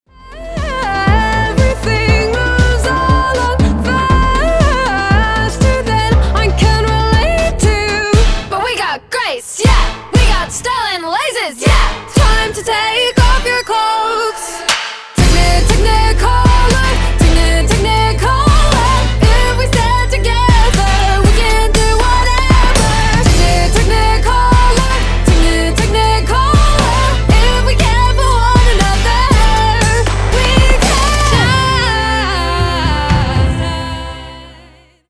• Качество: 320 kbps, Stereo